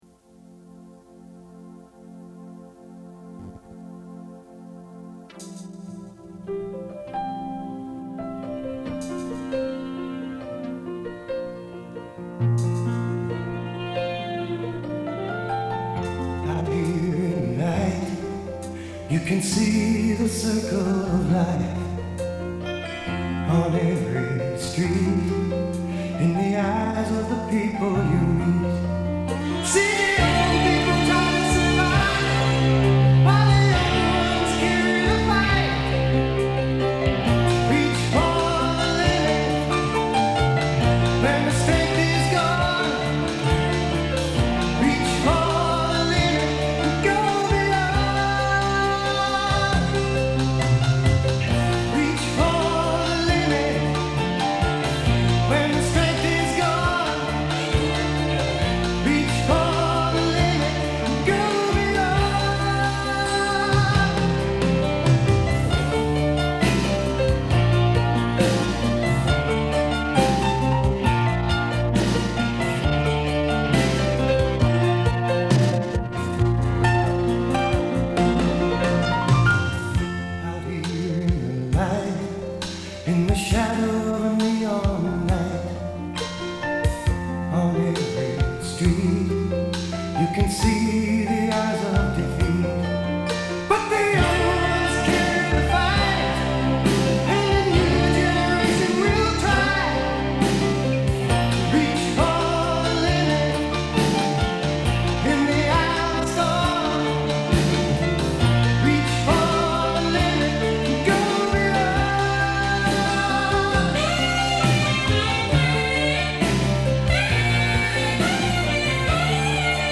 Pop / RockStories/HistoricalTime PassingBallad